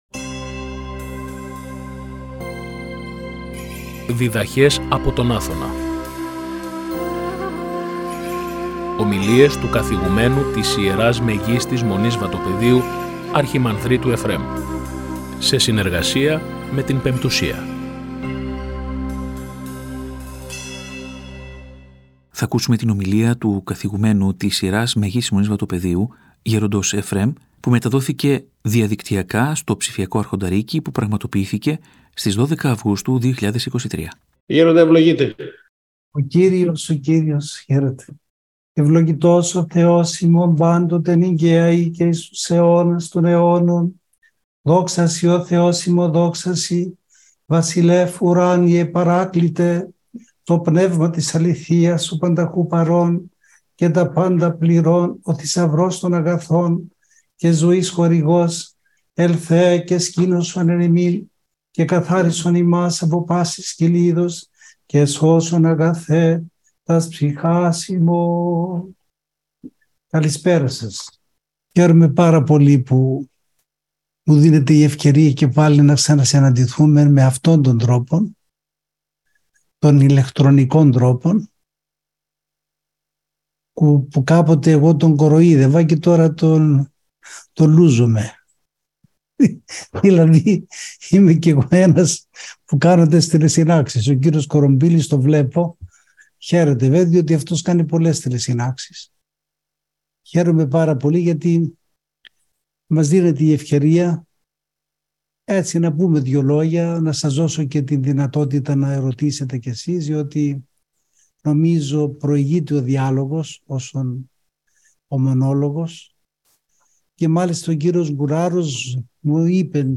Η ομιλία μεταδόθηκε και από την εκπομπή «Διδαχές από τον Άθωνα» στη συχνότητα του Ραδιοφωνικού Σταθμού της Πειραϊκής Εκκλησίας την Κυριακή 11 Αυγούστου 2024.